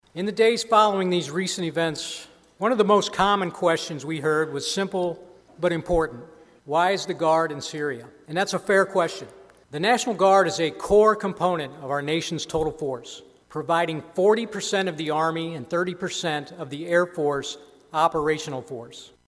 IOWA NATIONAL GUARD MAJOR GENERAL STEPHEN OSBORN USED THIS YEAR’S “CONDITION OF THE GUARD” SPEECH TO EXPLAIN WHY IOWA GUARDSMEN ARE DEPLOYED TO SYRIA AND HONOR THE TWO IOWA SOLDIERS KILLED THERE A MONTH AGO.